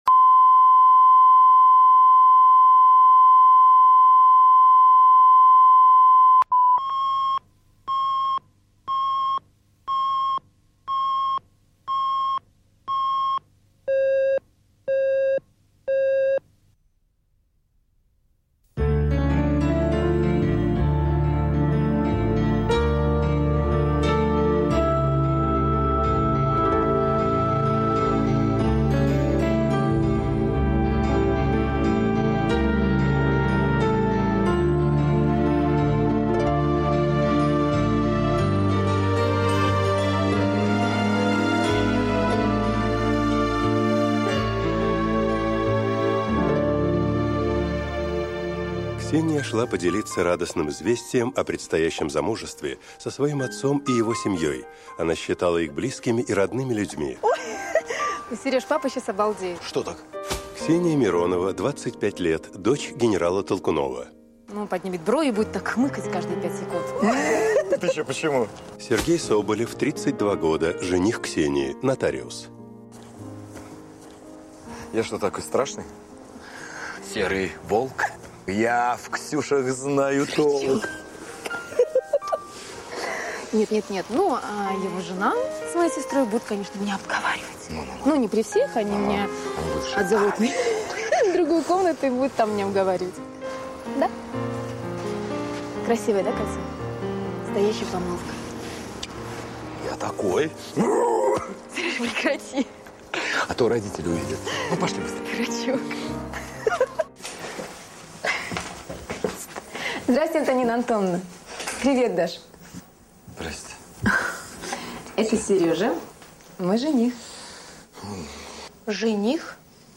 Аудиокнига Генеральская дочь | Библиотека аудиокниг
Прослушать и бесплатно скачать фрагмент аудиокниги